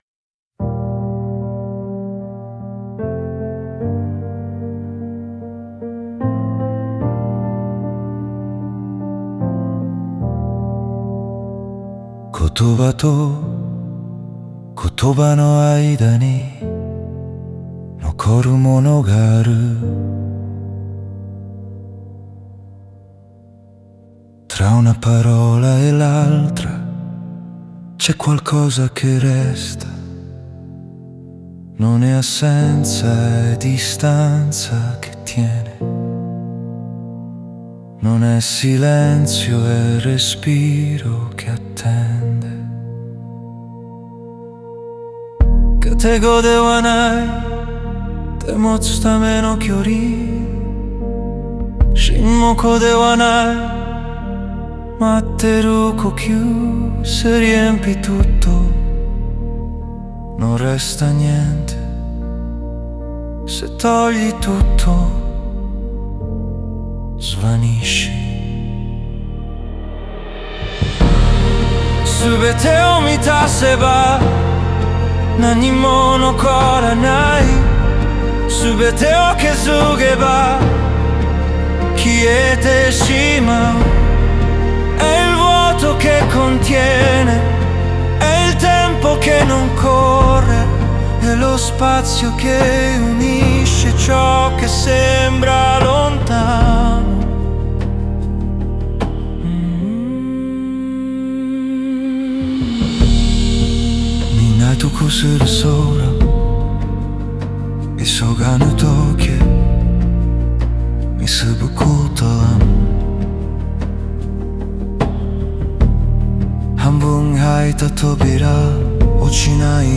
In questa versione del brano c’è solo una voce maschile.
Una voce vicina, bassa, trattenuta.
Il testo in italiano è essenziale, quasi parlato.
Ogni frase è separata da spazio, respiro, attesa.
Sostiene la voce senza anticiparla, senza seguirla, senza sovrastarla.
Il brano è costruito su una struttura minimale, priva di ritmo marcato o crescendo emotivi.
Piano, armonici, riverberi lunghi e decadenti creano un ambiente che non chiede attenzione, ma presenza.